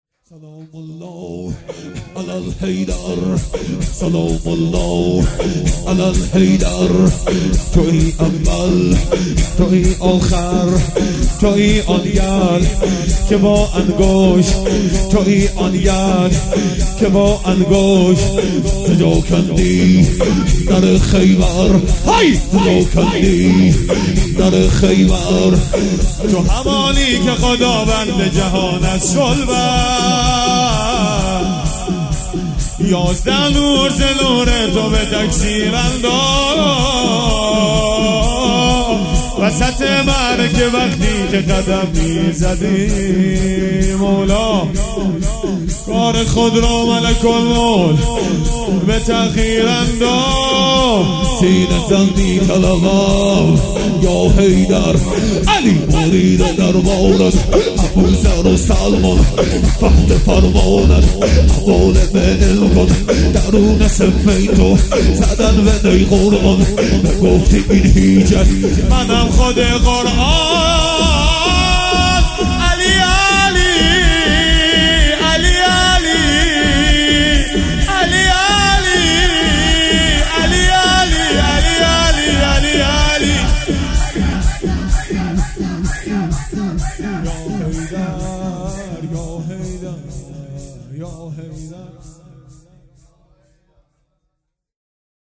شور ۲
روضه هفتگی ۲۸ مهر ۱۴۰۱